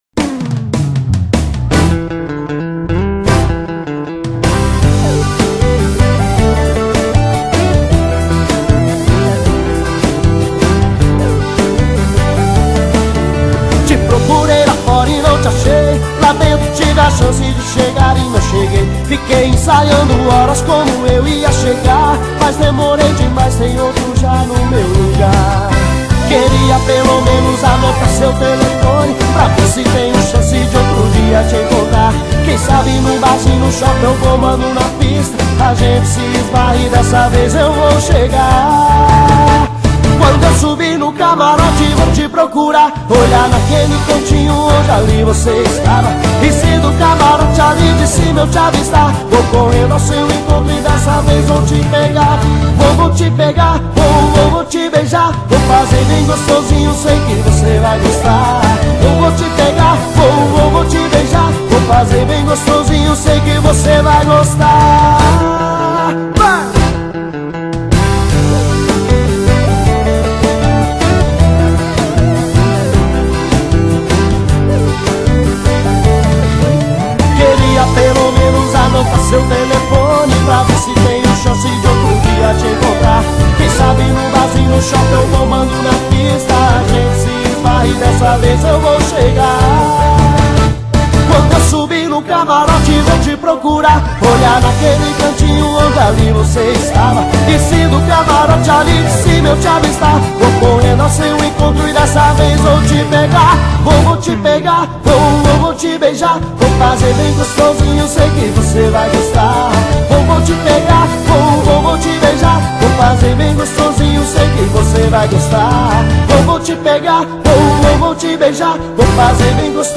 Forro Para Ouvir: Clik na Musica.